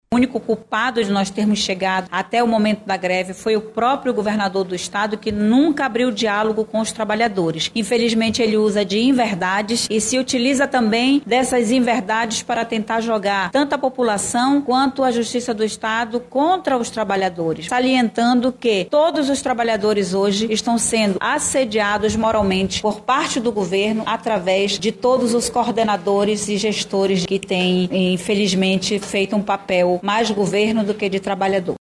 Na tarde dessa quinta-feira 01/06, na sede do órgão, localizada no Centro de Manaus, a presidente do Sindicato confirmou que a classe vai se reunir em uma nova assembleia extraordinária para decidir os rumos do movimento, sinalizando que a greve deve continuar.